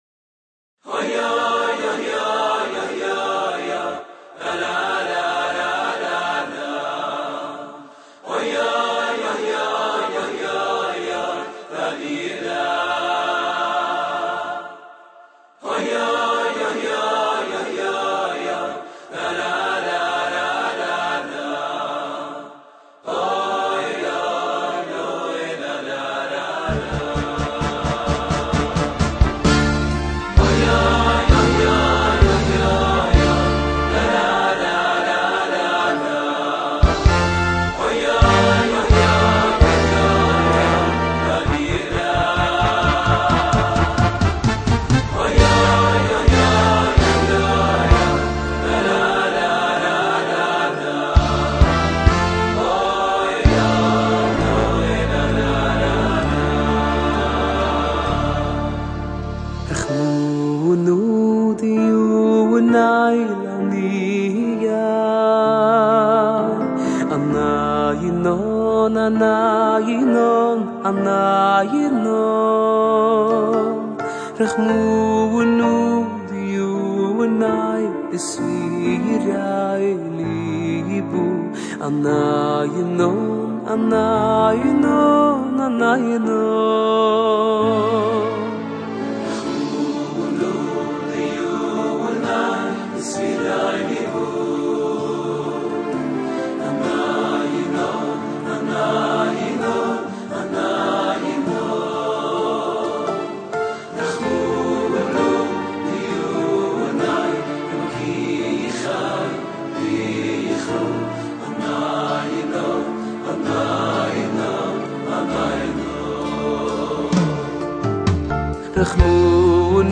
הלחן המרטיט